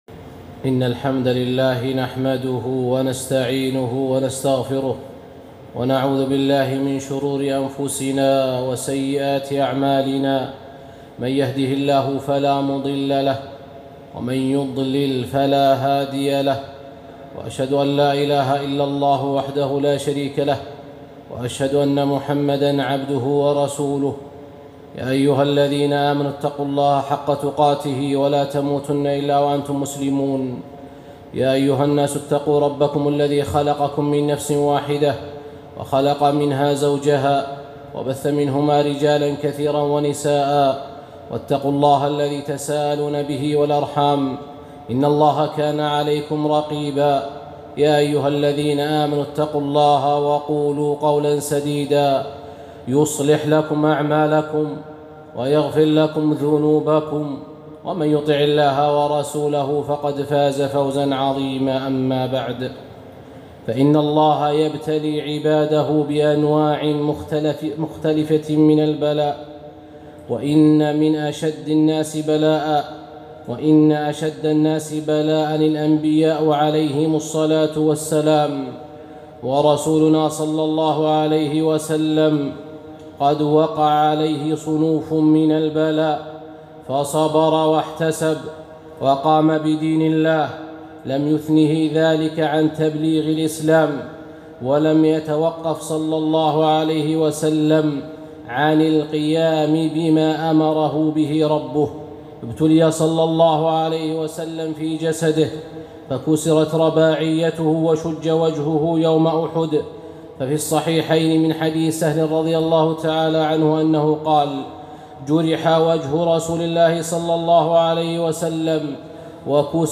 خطبة - قدوتنا في الصبر